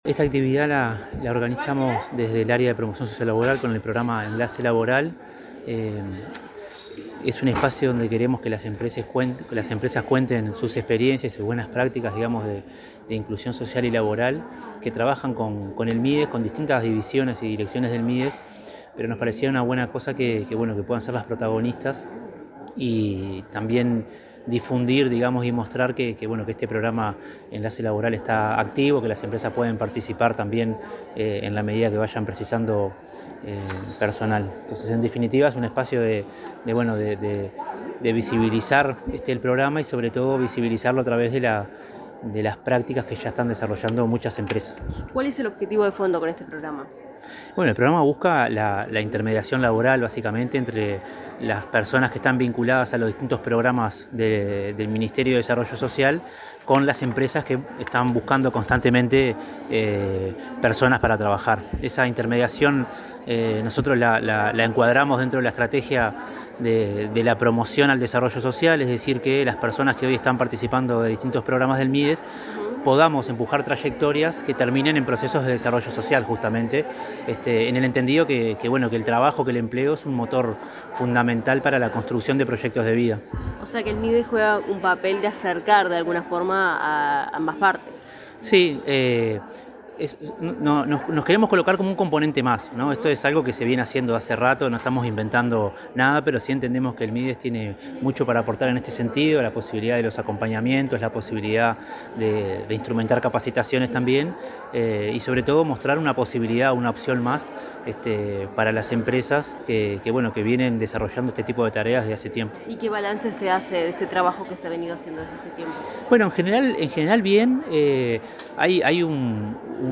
Declaraciones del director de Promoción Sociolaboral, Camilo Álvarez
En el marco del conversatorio Buenas Prácticas de Inclusión Sociolaboral, el director de Promoción Sociolaboral, Camilo Álvarez, realizó declaraciones